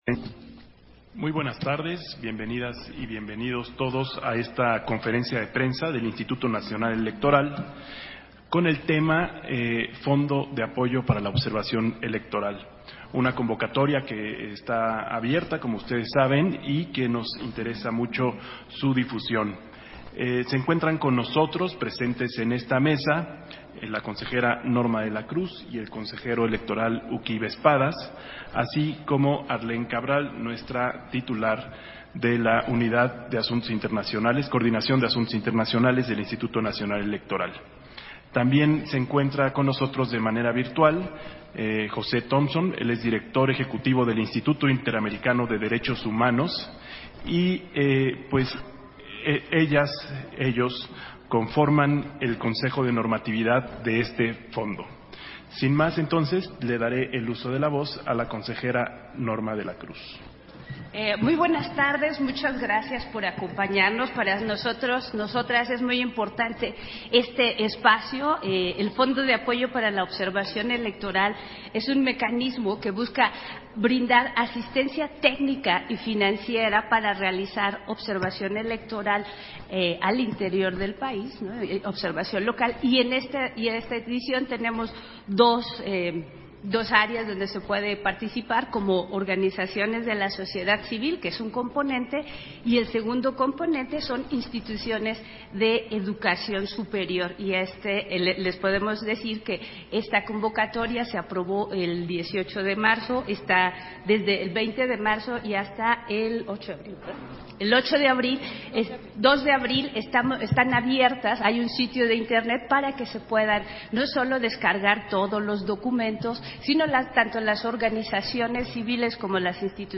Audio de la conferencia de prensa del Fondo de Apoyo para la Observación Electoral (FAOE)